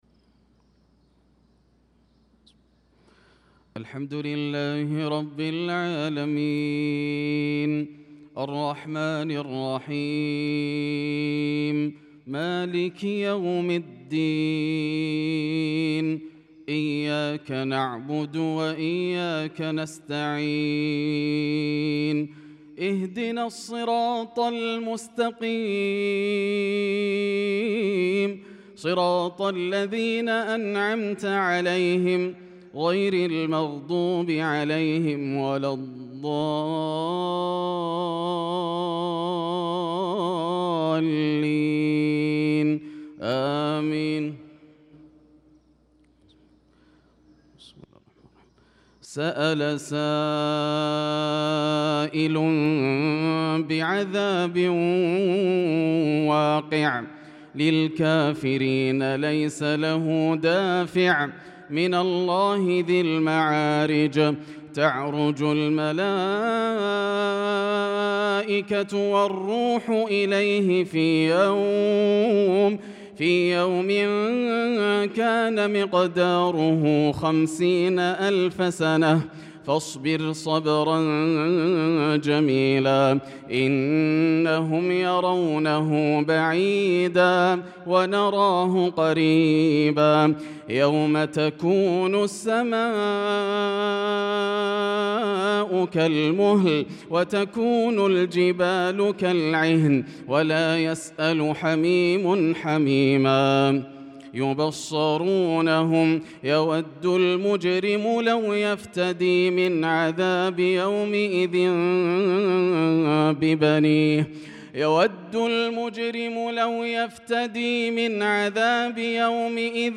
صلاة الفجر للقارئ ياسر الدوسري 19 شوال 1445 هـ
تِلَاوَات الْحَرَمَيْن .